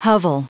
Prononciation du mot hovel en anglais (fichier audio)
Prononciation du mot : hovel